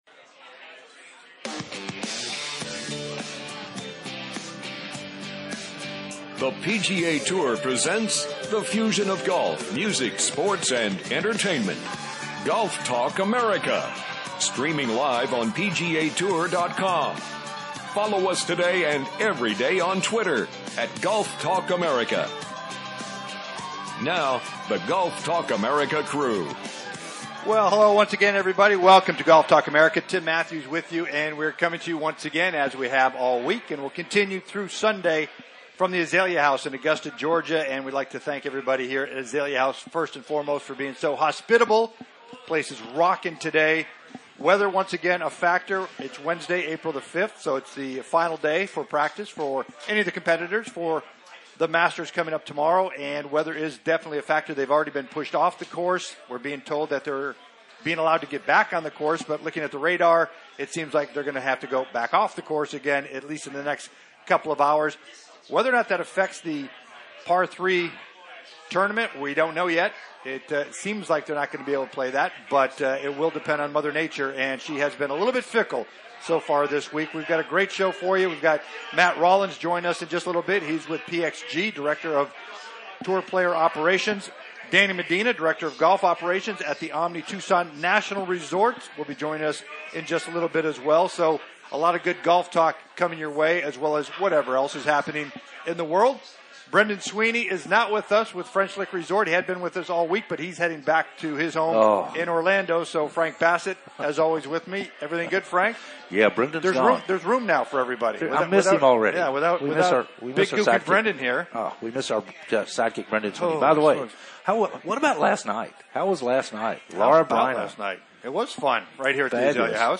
Wednesday "LIVE" FROM THE MASTERS